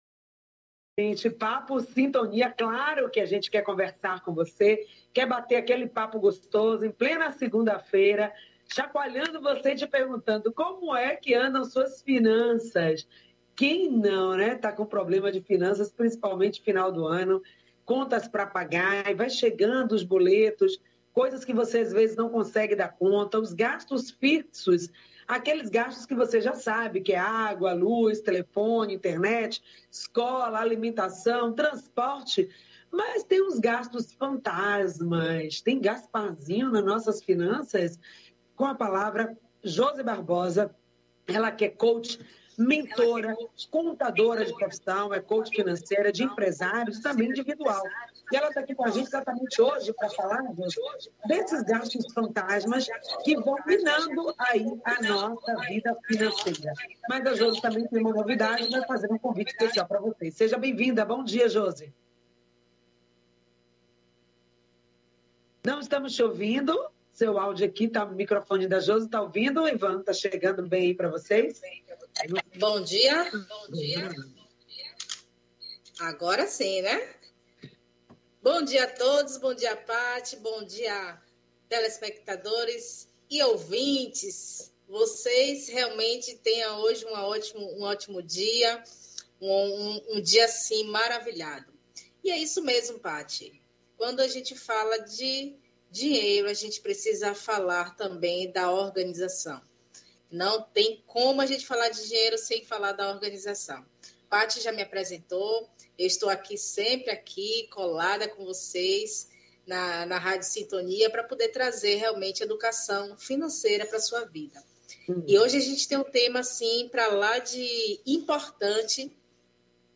O programa Em Sintonia acontece de Segunda à sexta das 9 às 10h, pela Rádio Excelsior AM 840.